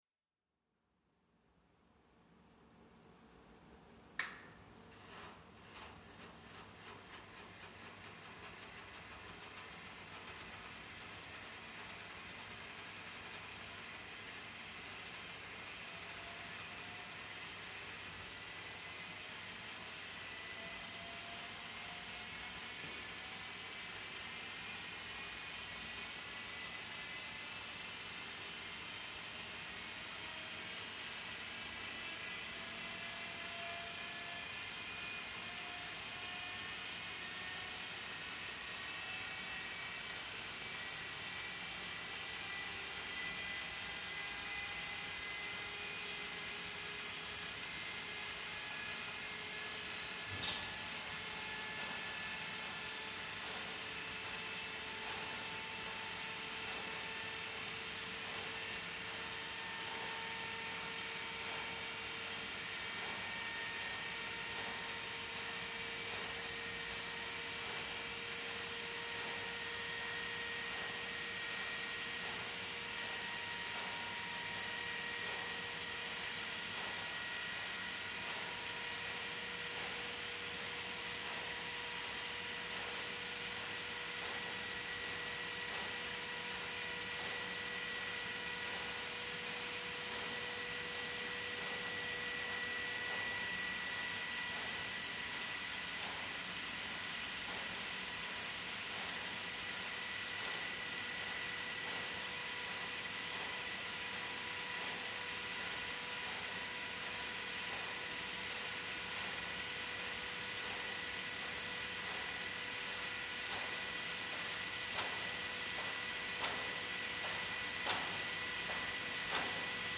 Colossus Start Sequence
Boot Sequence of Colossus. DPA stereo mix located close to the tape mechanism and motor with MKH416 located near main relay board. Manually fading between the two configurations for dramatic illustration.